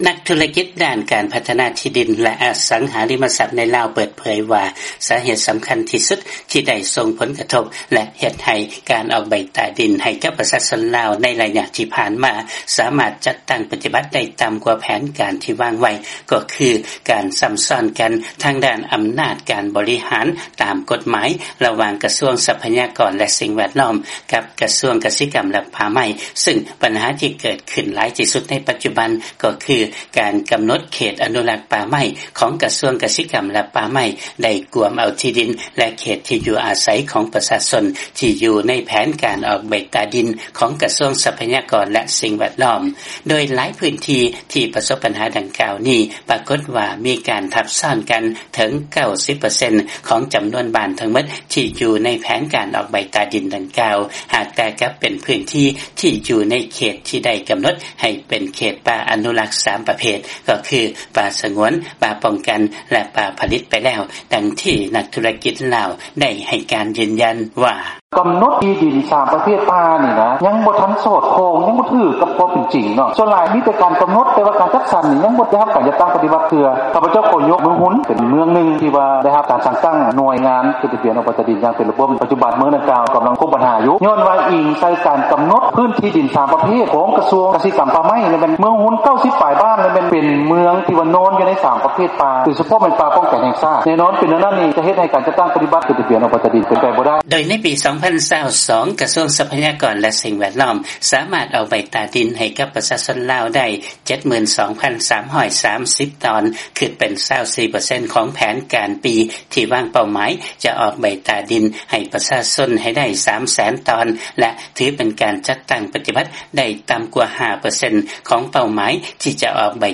ຟັງລາຍງານ ການຊໍ້າຊ້ອນກັນທາງດ້ານອຳນາດການບໍລິຫານ ລະຫວ່າງໜ່ວຍງານຂອງລັດຖະບານ ລາວ ເຮັດໃຫ້ການອອກໃບຕາດິນ ປະຕິບັດໄດ້ຕໍ່າກວ່າແຜນທີ່ວາງໄວ້